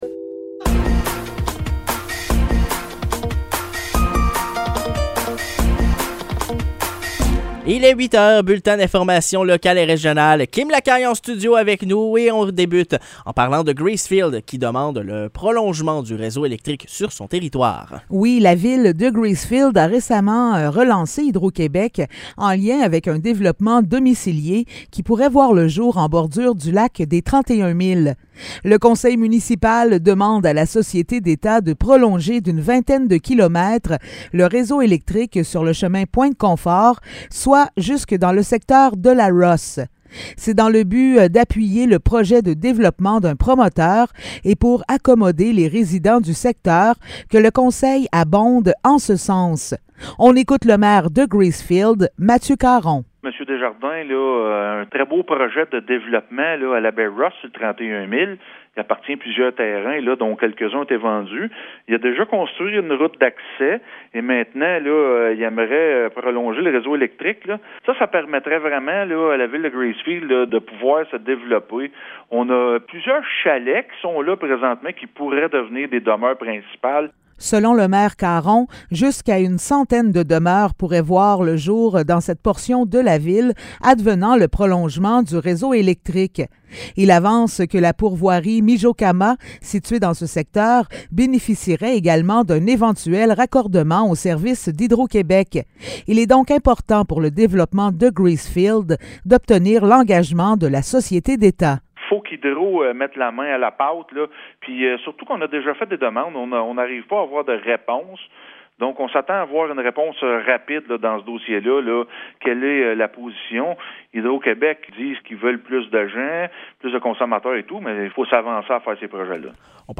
Nouvelles locales - 4 juillet 2023 - 8 h